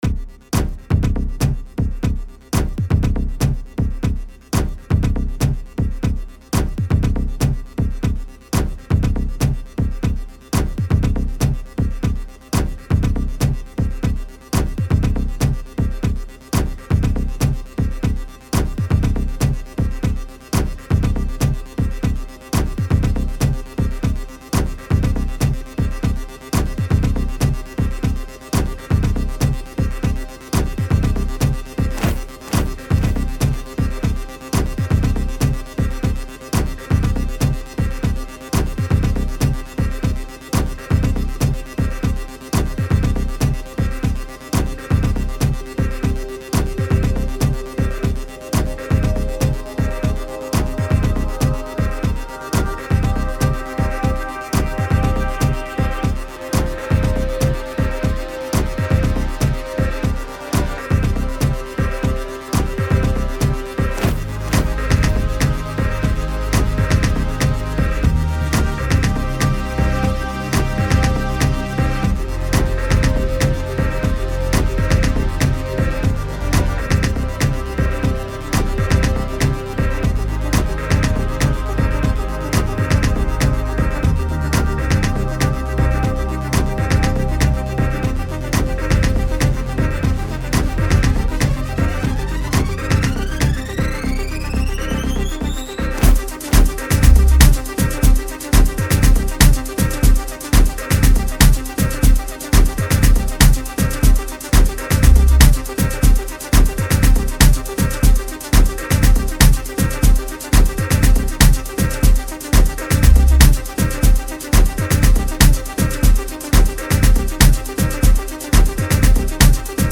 Indulge in the captivating rhythms of Afro House